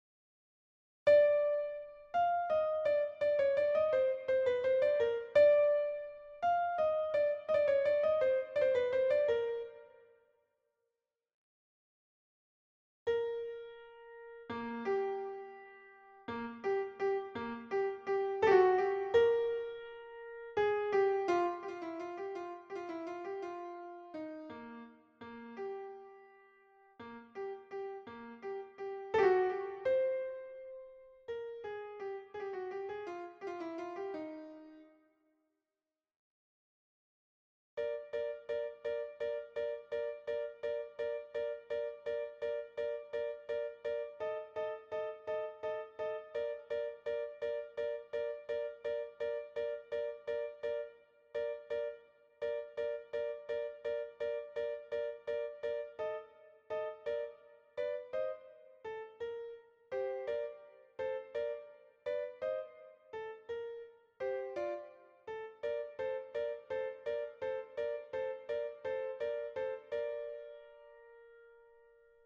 Tenors -
La_Traviata_TENORS_Libiamo_ne_lieti_calici.mp3